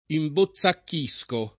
vai all'elenco alfabetico delle voci ingrandisci il carattere 100% rimpicciolisci il carattere stampa invia tramite posta elettronica codividi su Facebook imbozzacchire v.; imbozzacchisco [ imbo ZZ akk &S ko ], -sci